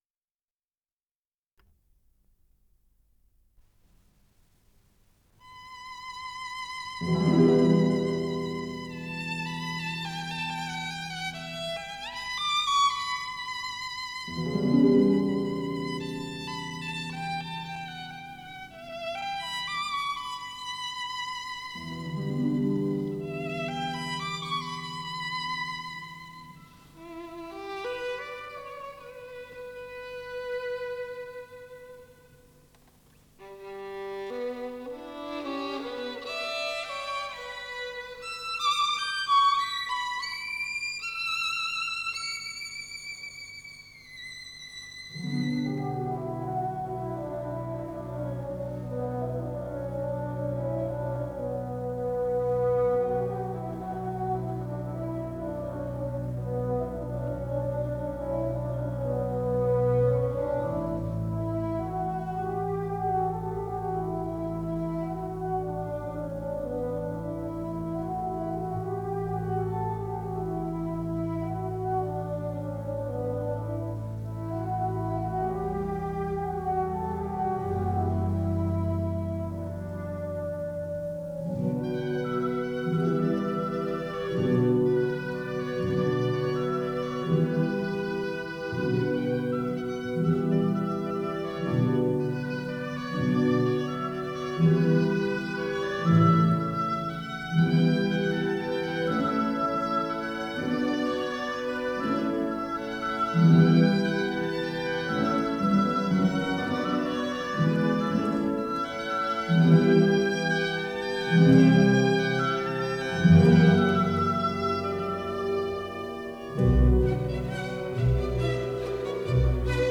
Исполнитель: Симфонический оркестр СССР
Симфоническая сюита
ми мажор